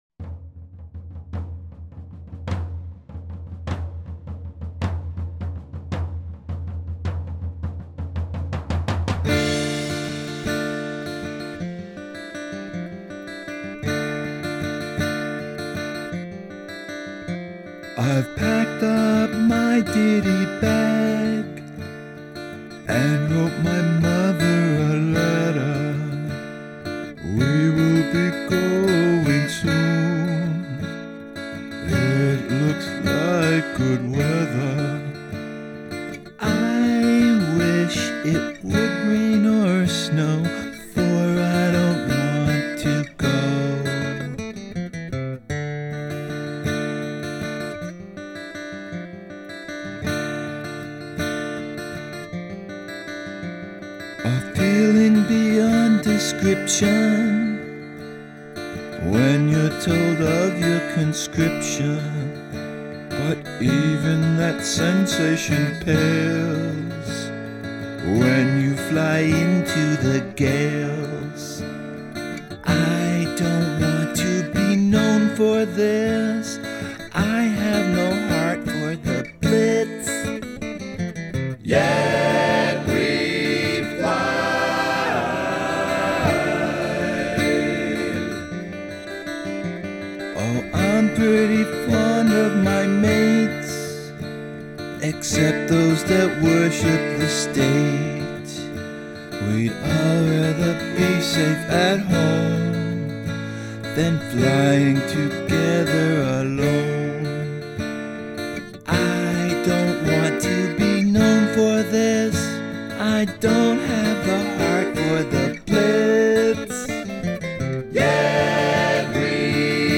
Use of choral voices
Beautiful guitar playing!